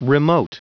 Prononciation du mot remote en anglais (fichier audio)
Prononciation du mot : remote